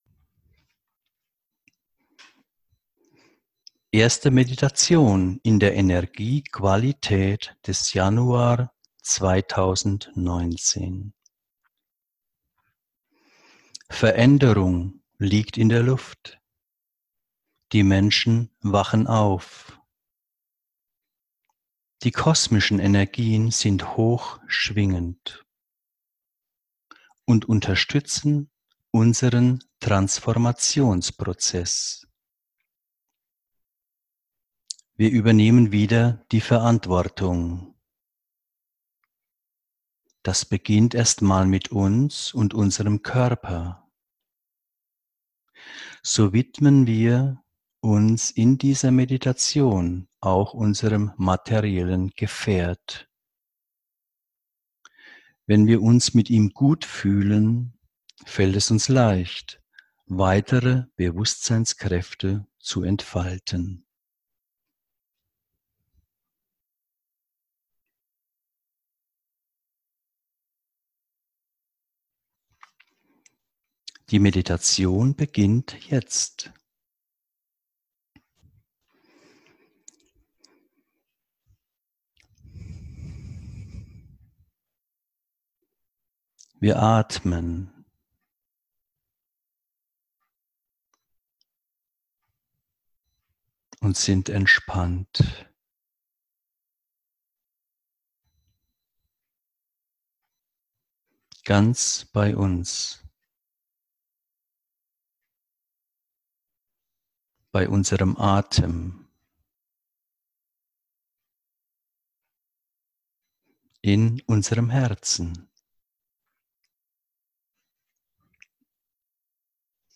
Diese geführte Meditation lädt dich ein, Verantwortung für deinen Körper zu übernehmen.